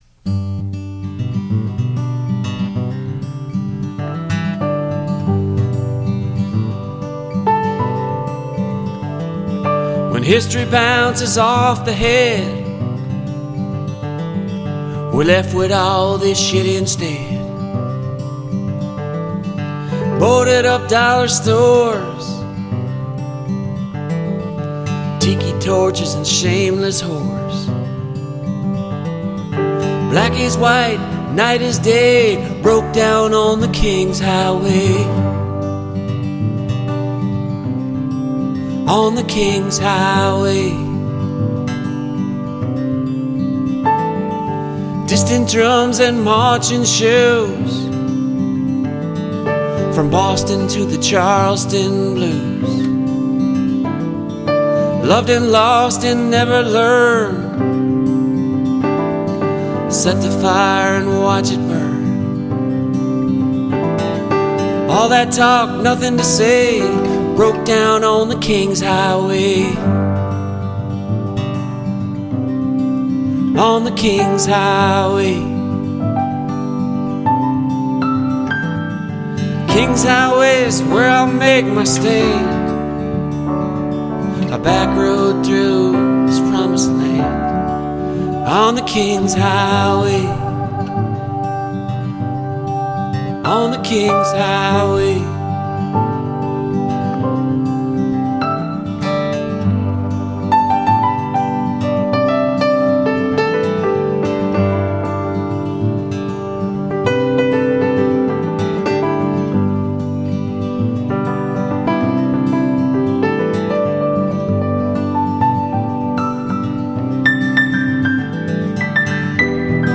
guitar, vocals, harmonica
piano, organ